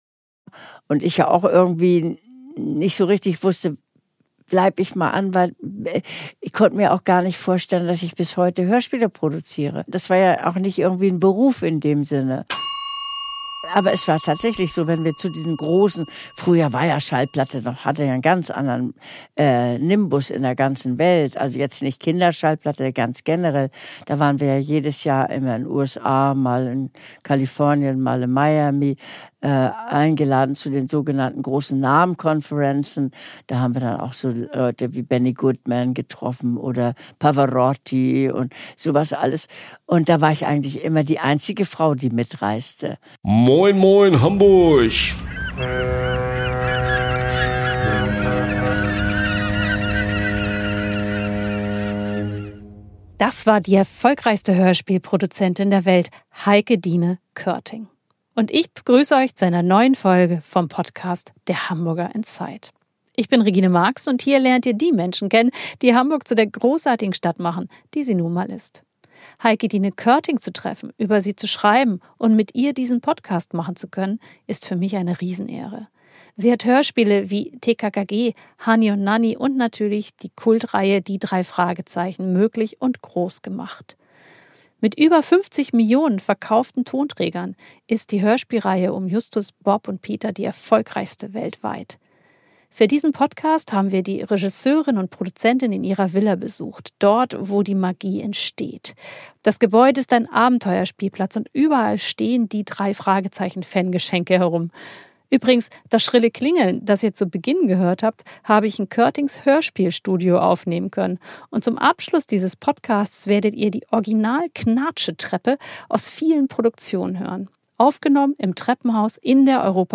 Ein Gespräch über Abenteuer, Freundschaften und darüber, warum sie auch mit 80 noch Ja sagt.
Ein Besuch in der ikonischen Villa in der Rothenbaumchaussee – dem Herz des Hörspieluniversums rund um TKKG, Hanni & Nanni und natürlich Die drei ???